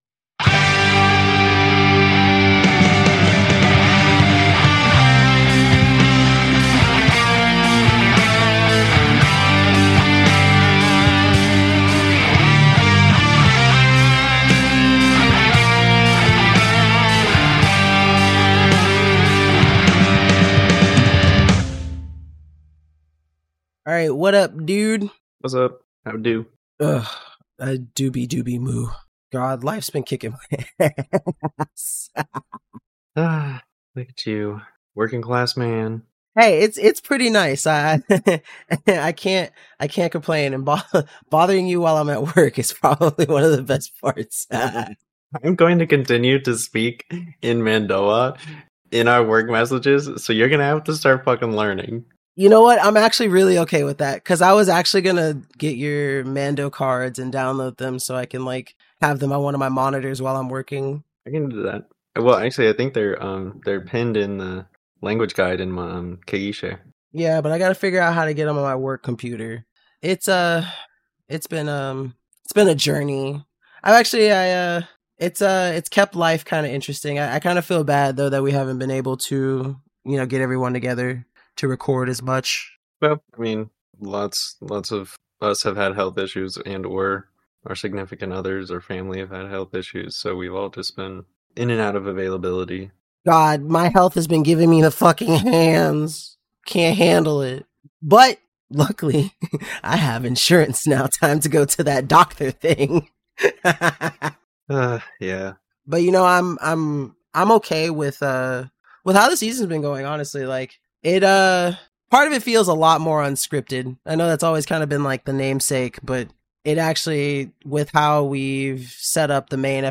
Four friends explore, discover and unravel the mysteries of the Star Wars universe, diving into both the Canon and the Legends timeline to give you all the Star Wars content you never knew you needed.